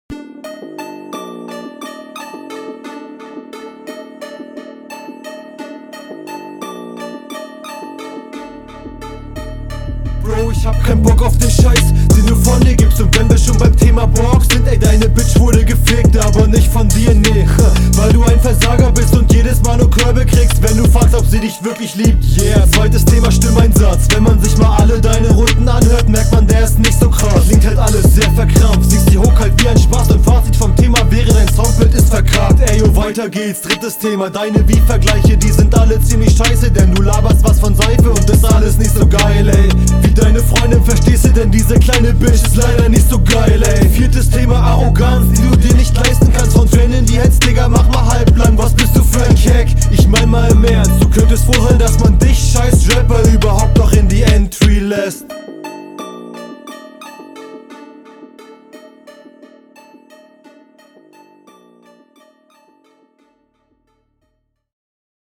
Liebe diese arrogante Stimmenlage und die dazugehörigen Betonungen.
bin klingt schon mal intense. einstieg kommt schon recht fett. deine stimme passt ganz nice.